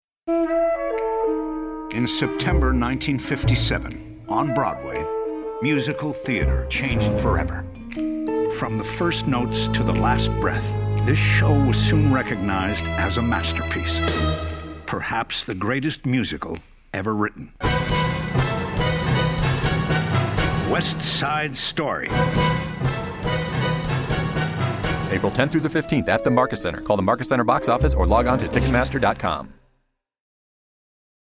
West Side Story Radio Commercial